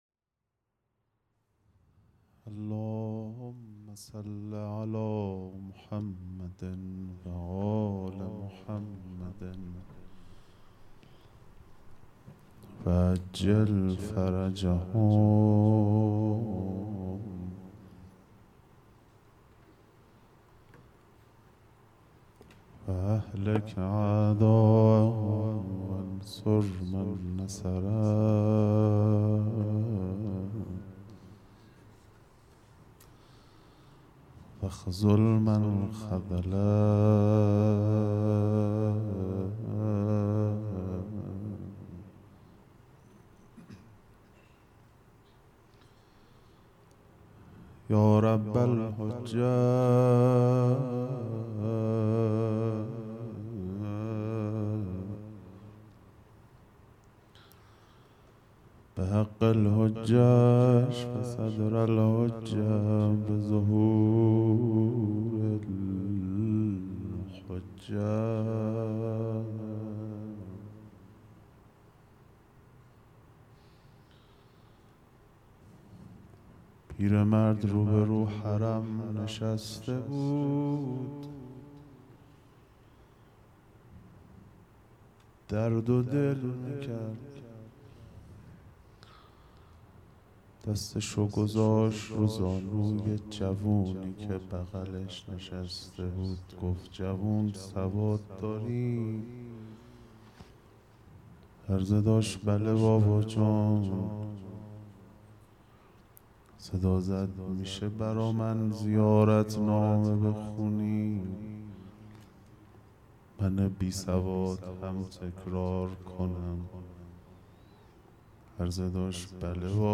هیئت مکتب الزهرا(س)دارالعباده یزد - روضه